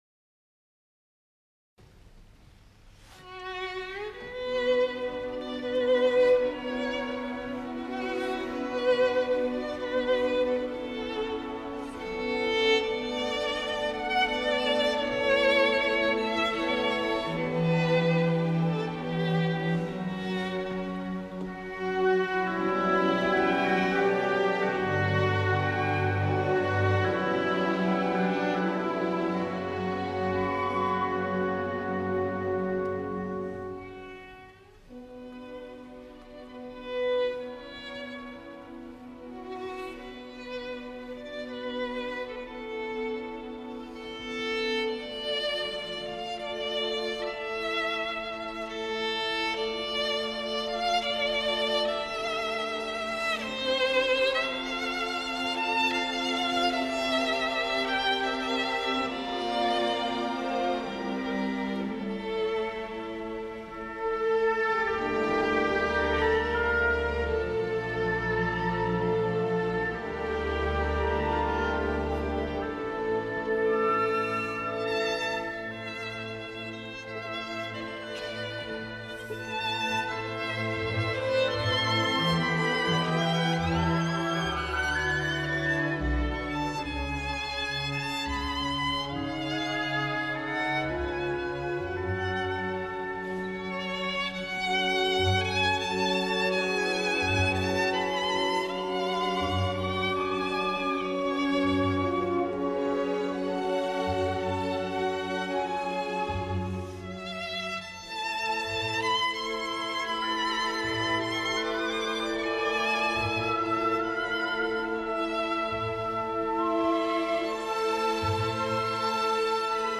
dazu gehört die behutsame besinnlichkeit des
violinkonzertes von j. rodrigo.